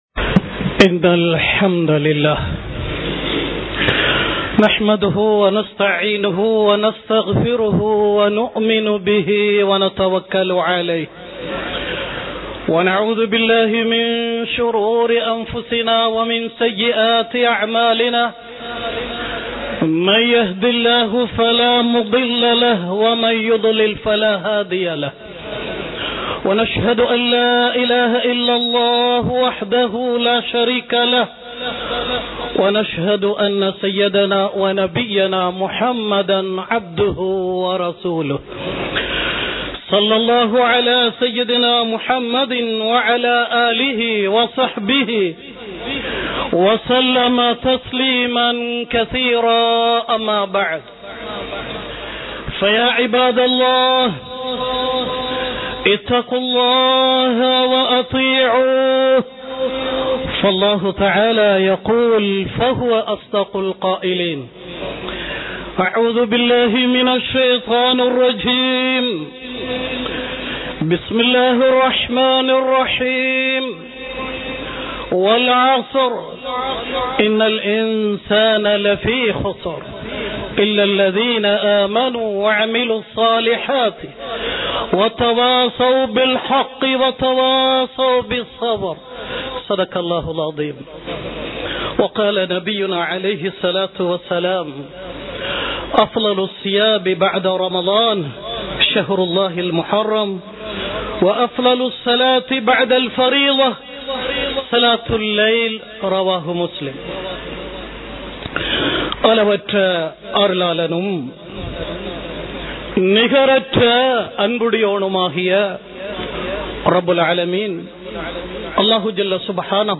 இஸ்லாம் கூறும் சிறப்பான மாதங்கள் | Audio Bayans | All Ceylon Muslim Youth Community | Addalaichenai